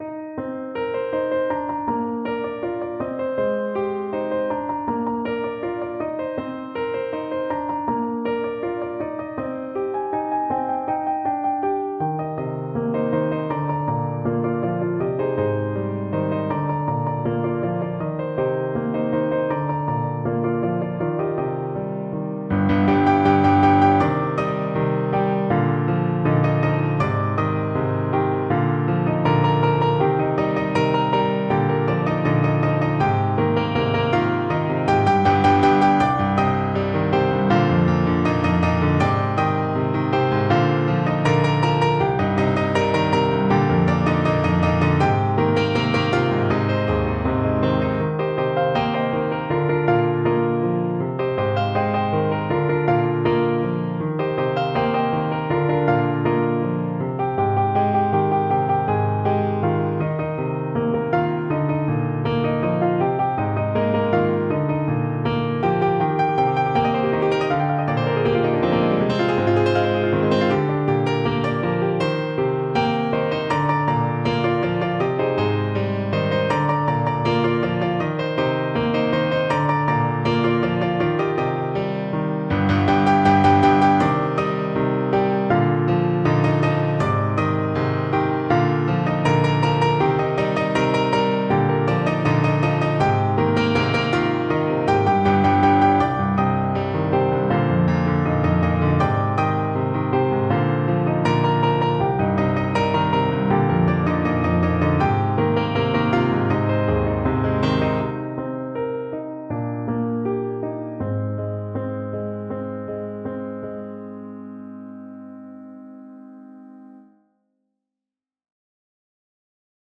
Ноты для фортепиано.
*.mid - МИДИ-файл для прослушивания нот.